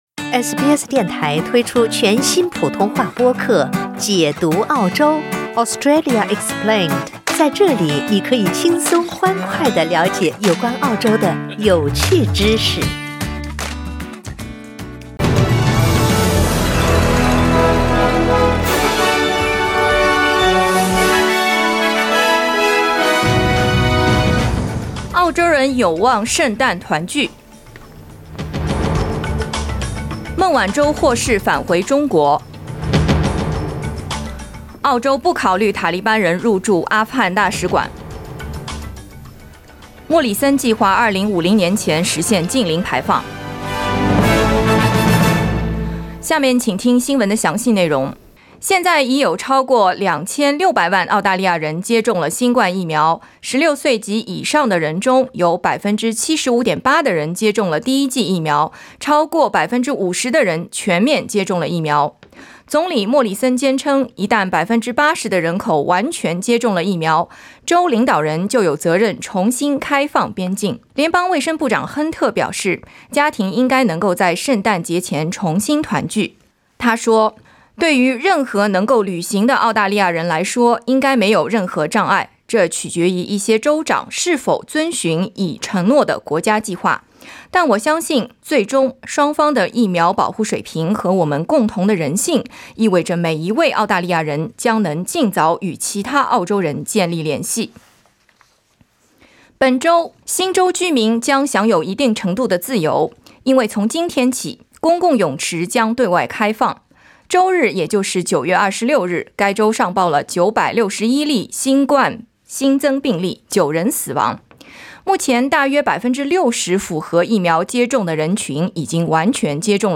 SBS早新闻（2021年9月27日）
SBS Mandarin morning news Source: Getty Images